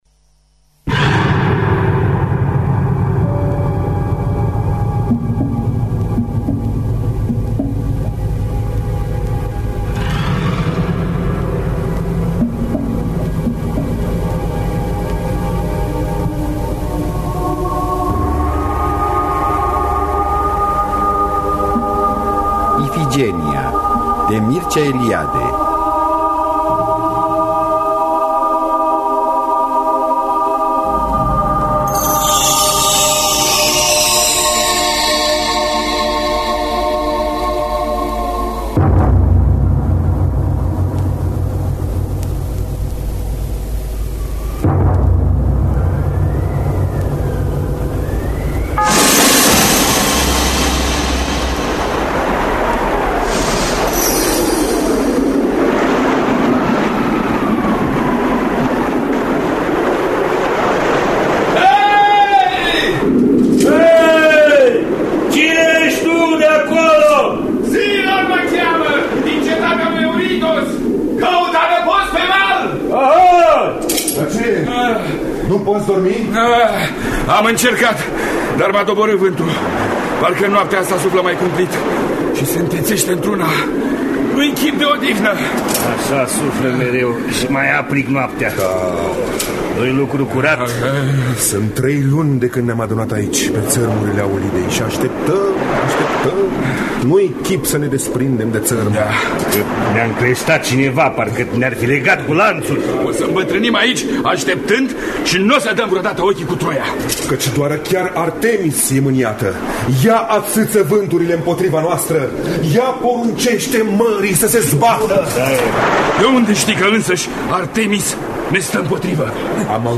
Adaptare radifonică